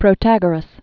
(prō-tăgər-əs) fl. fifth century BC.